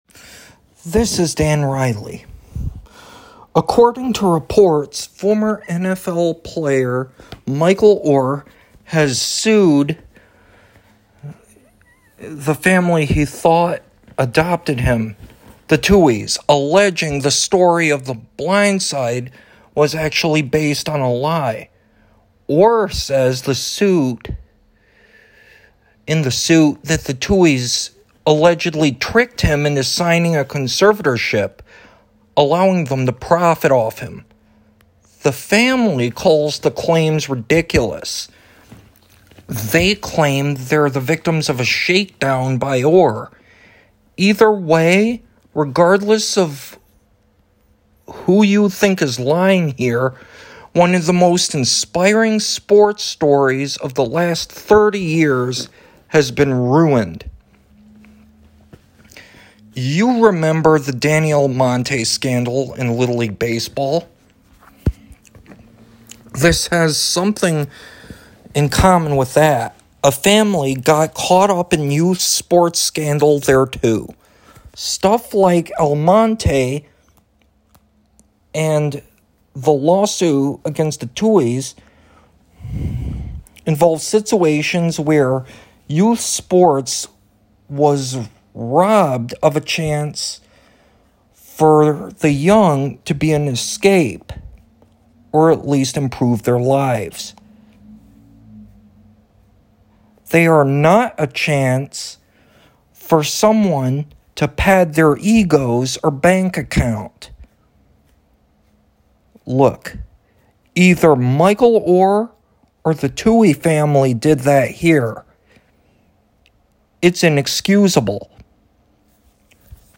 Audio Commentary